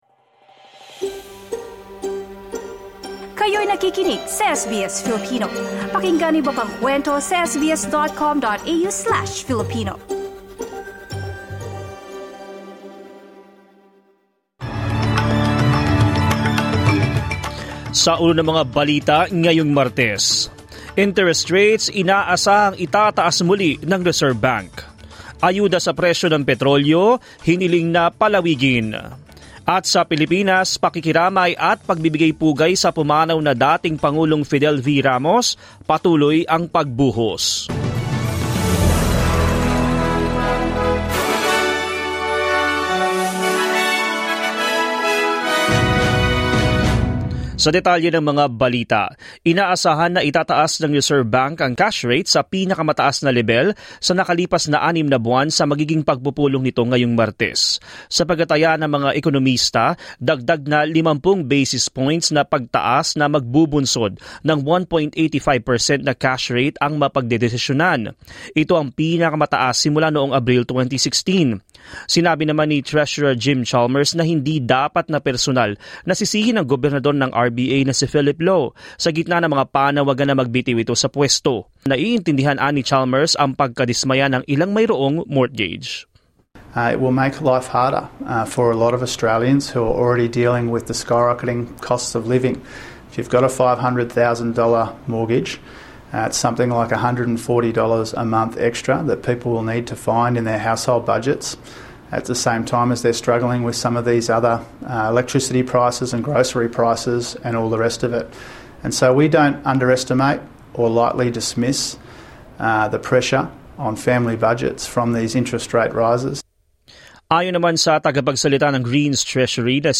SBS News in Filipino, Tuesday 2 August